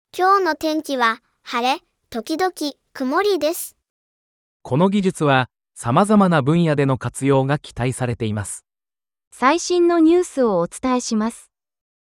音六(おとろく)AI｜TBSのノウハウ搭載のAIナレーションシステム
おとろくでカンタン操作でテキストが音声になる！TBSのノウハウを詰め込んだAIナレーションシステム